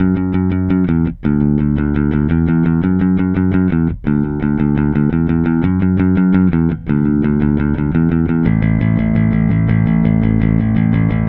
Indie Pop Bass 01.wav